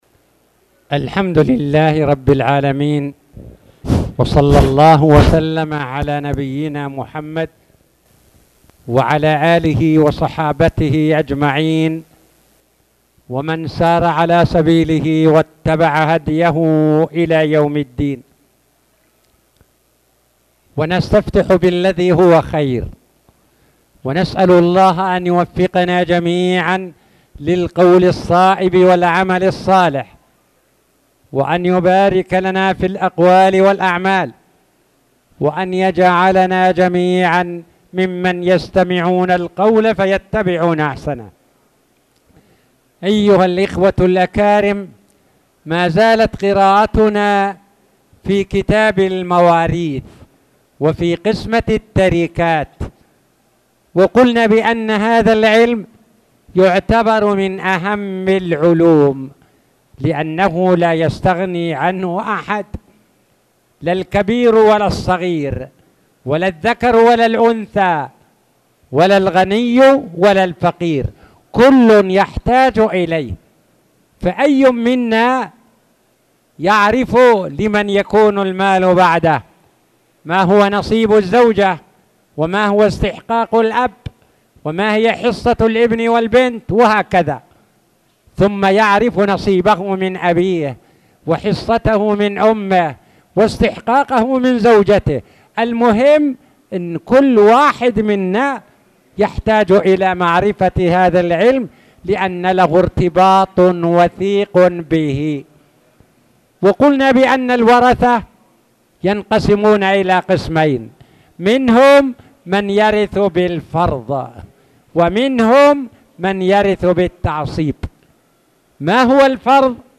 تاريخ النشر ٧ ذو القعدة ١٤٣٧ هـ المكان: المسجد الحرام الشيخ